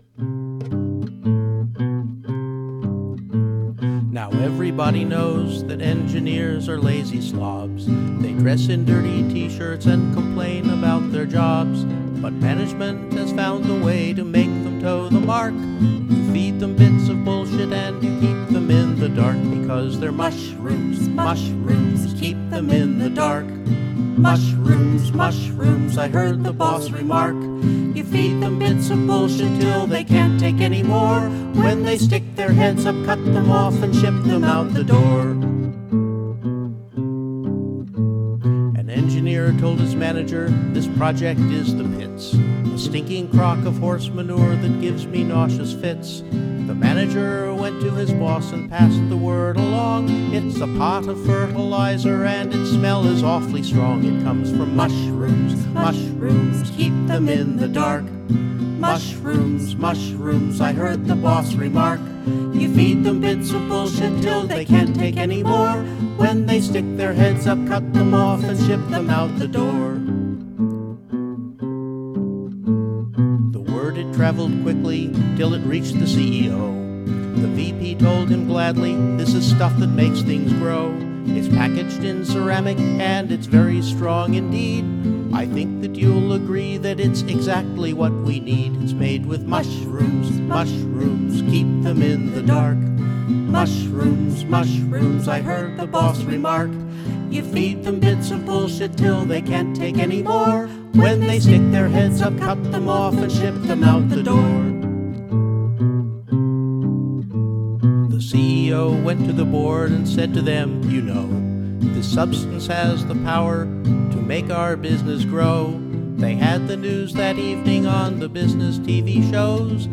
They're not necessarily in any shape to be heard!